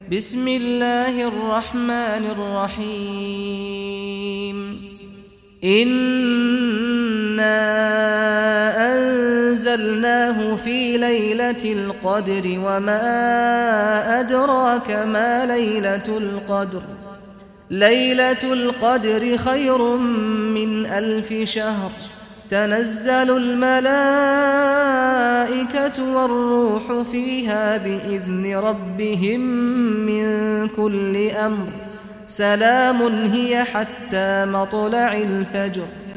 Surah Al-Qadr Recitation by Sheikh Saad al Ghamdi
Surah Al-Qadr, listen or play online mp3 tilawat / recitation in Arabic in the beautiful voice of Sheikh Saad al Ghamdi.